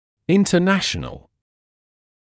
[ˌɪntə’næʃnəl][ˌинтэ’нэшнл]международный